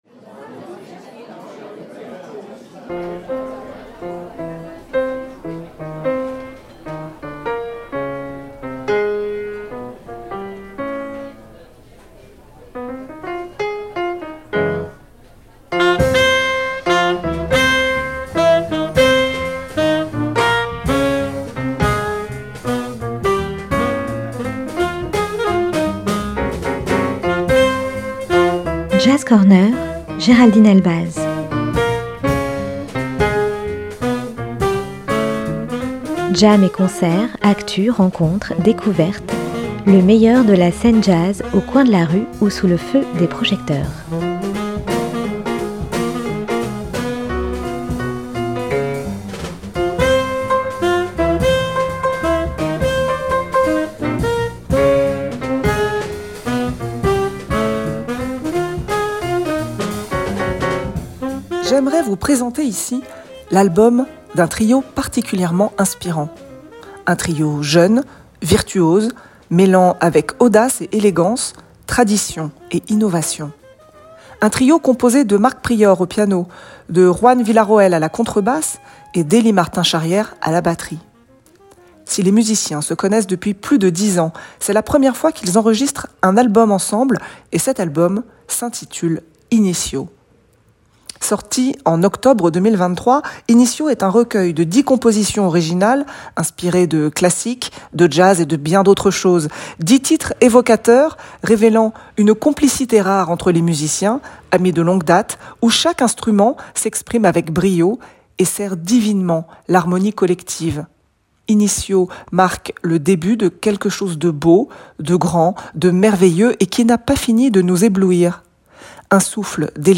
JAZZ CORNER, lundi et jeudi à 13h et 18h. Chronique